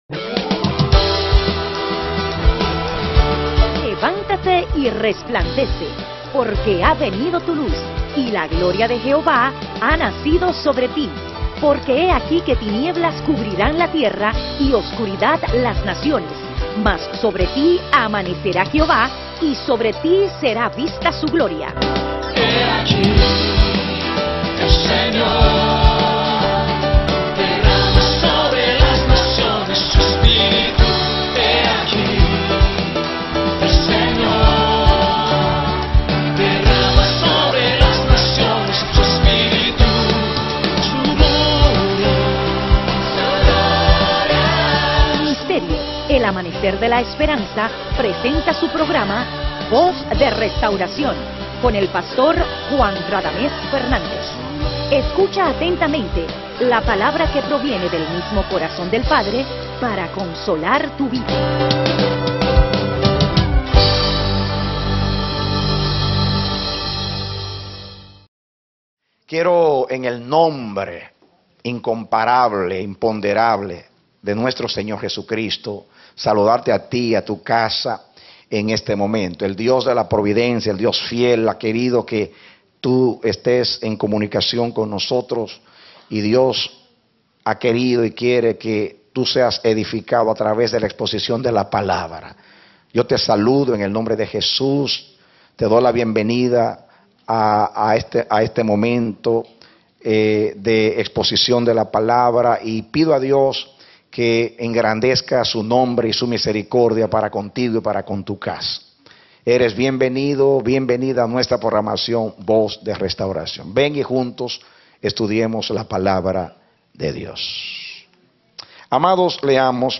A mensaje from the serie "Mensajes."